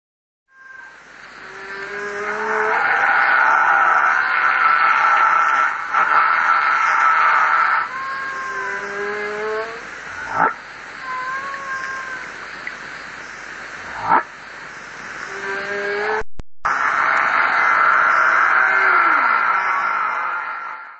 Whales
1989 Humpback whale song.